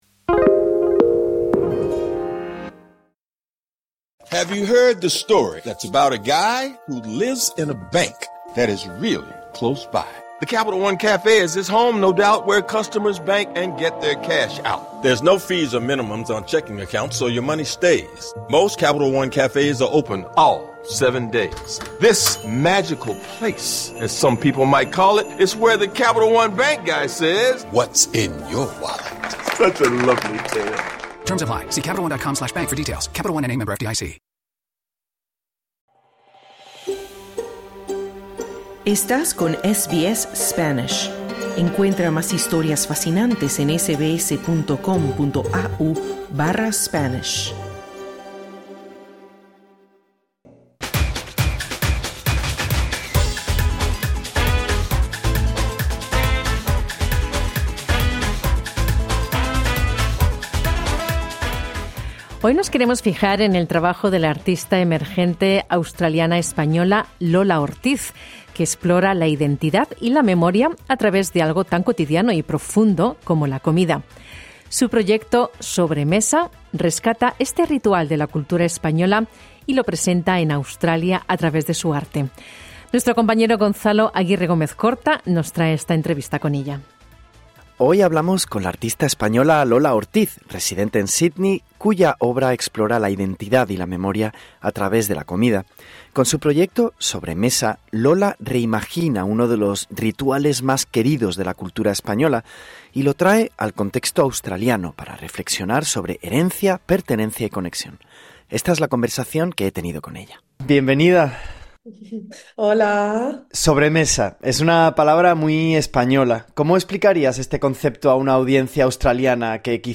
conversamos con la artista